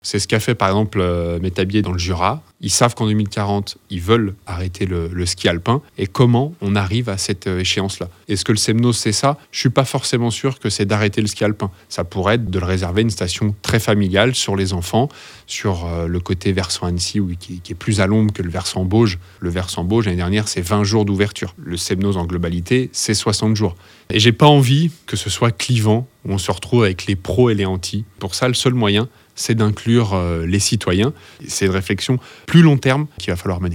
Alexandre Mulatier Gachet, Premier adjoint à la ville d’Annecy :